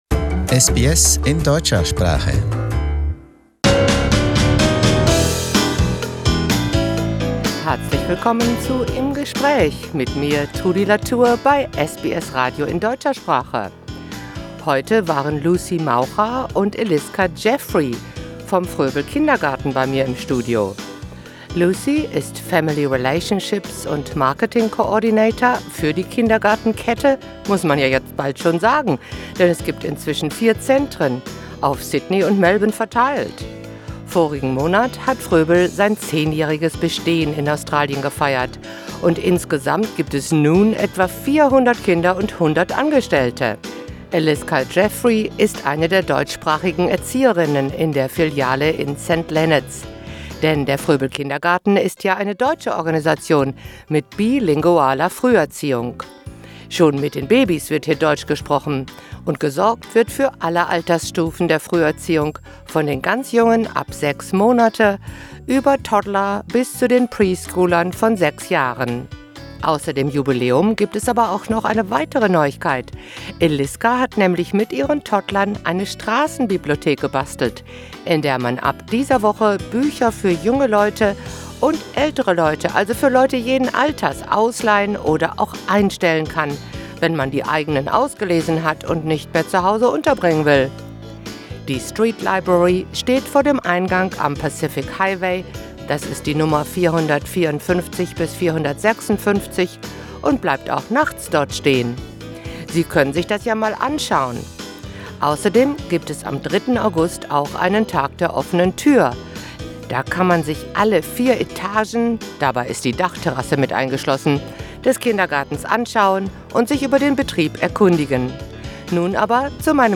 Im Gespäch: Eine vielsprachige Strassenbibliothek